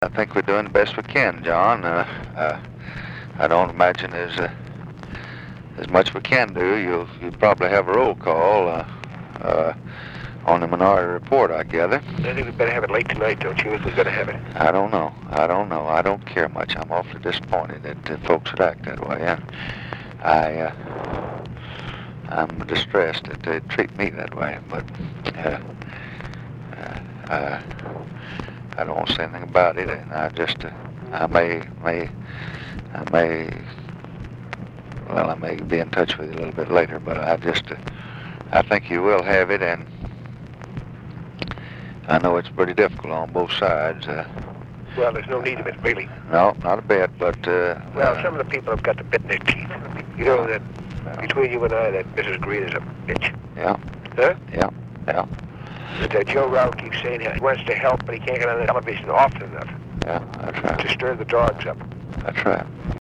On the convention’s first day, DNC chairman John Bailey called to update the President on the credentials committee fight, and to complain about one of the MFDP’s key backers, Oregon congresswoman Edith Green.